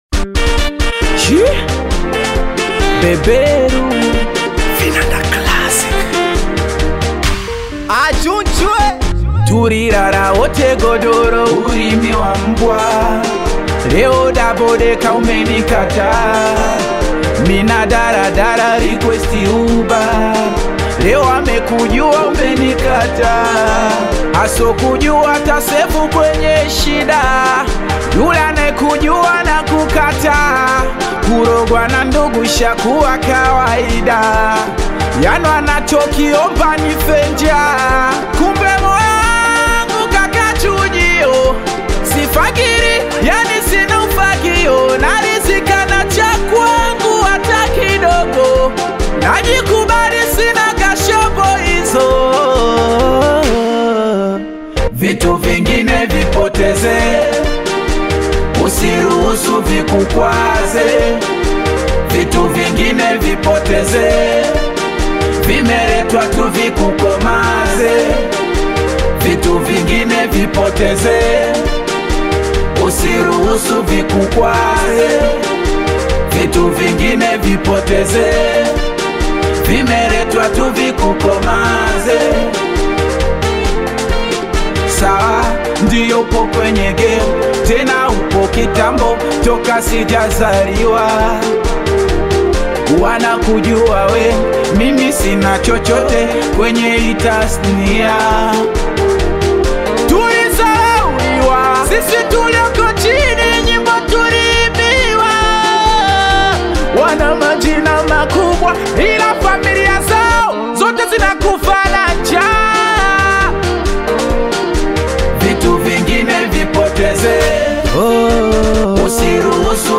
Singeli music track
Tanzanian Bongo Flava Singeli